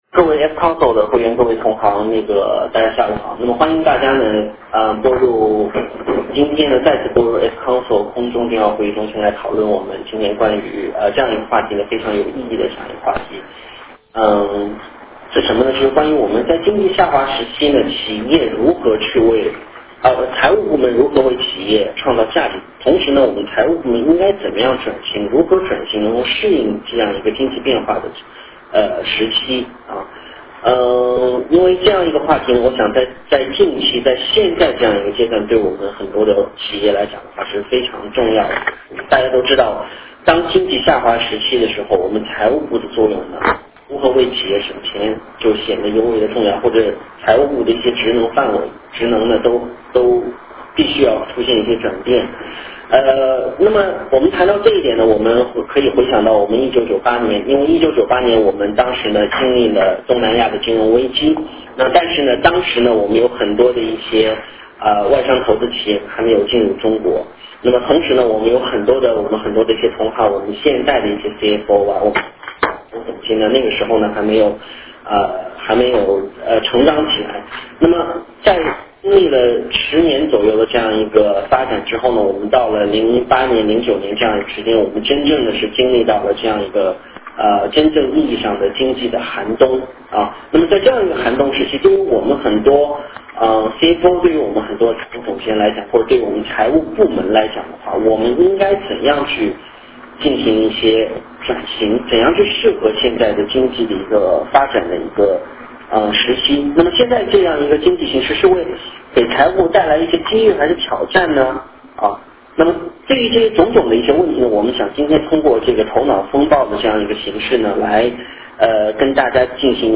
活动形式： 头脑风暴式电话会议 会议安排： Part 1 思维龙卷风 将对以下问题进行轮流讨论 1． 经济危机，给财务部带来了那些机遇和挑战?在此过程中，财务总监的自身定位是什么？ 2． 在成本控制的风潮中，控制的方向何在？如何找到最易执行的成本控制策略？